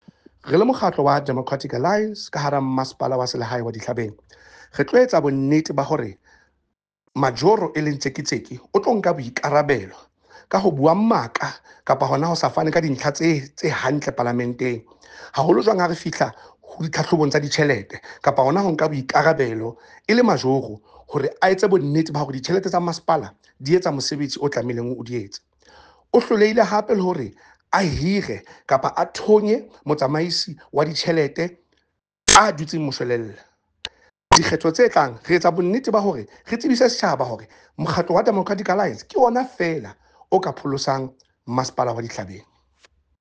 Sesotho soundbites by Cllr Eric Motloung and